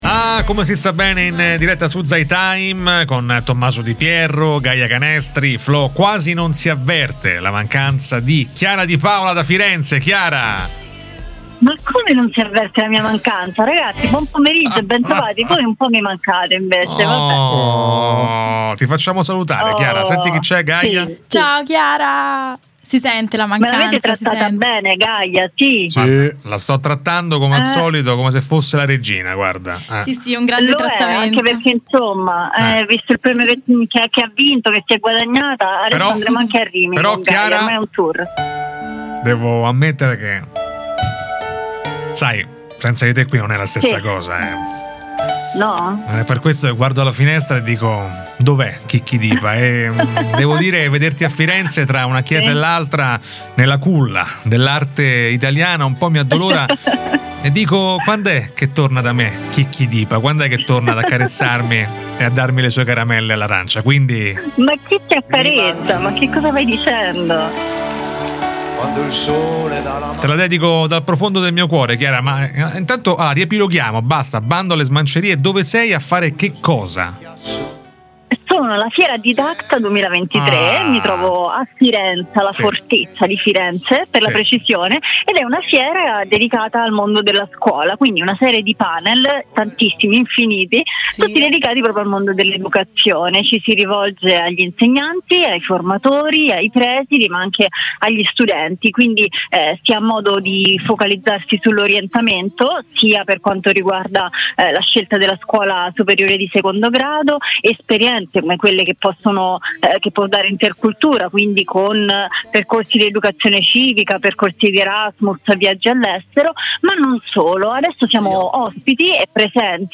la nostra speaker in diretta da Didacta Italia a Firenze, la principale manifestazione sulla scuola italiana e la formazione degli insegnanti